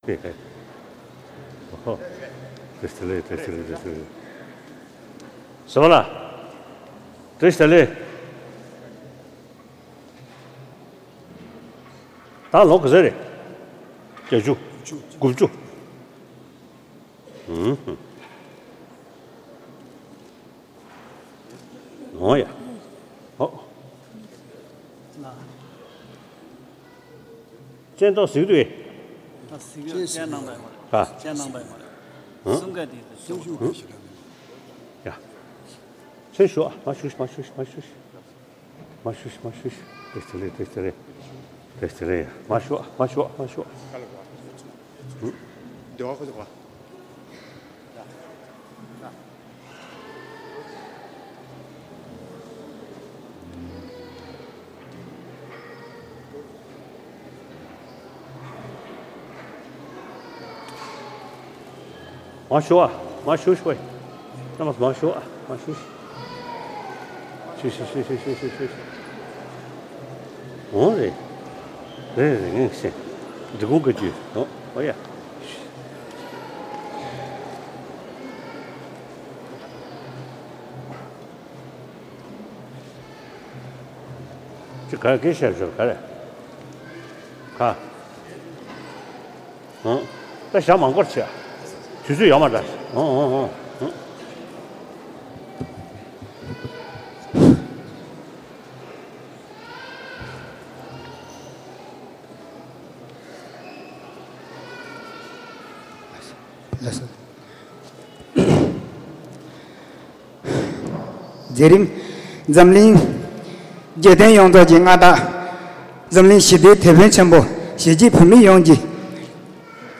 ཕྱི་ཚེས་ ༢༦ ཉིན་༸གོང་ས་མཆོག་ནས་ཨ་རིའི་གྲོང་ཁྱེར་ཆི་ཁ་ཀོ་ས་གནས་བོད་མི་༦༠༠ལྷག་བཀའ་སློབ་བསྩལ་གནང་མཛད་ཡོད་པ།